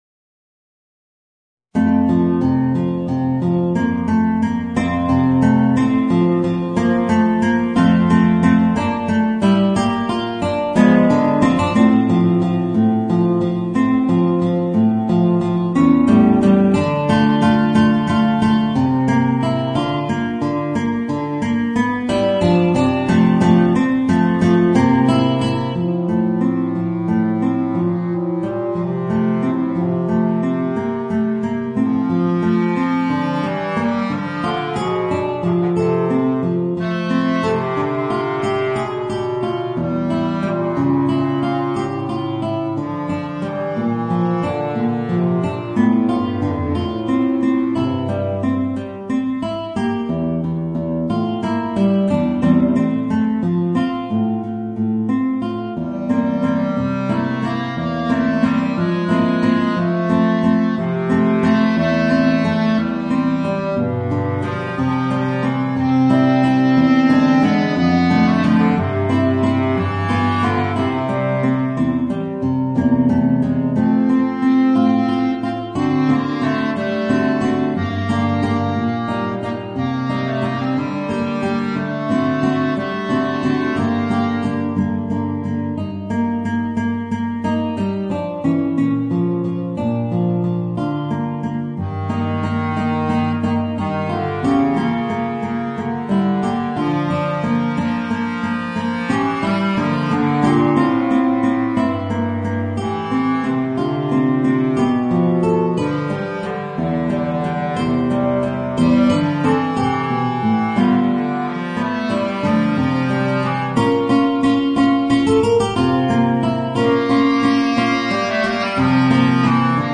Voicing: Guitar and Bass Clarinet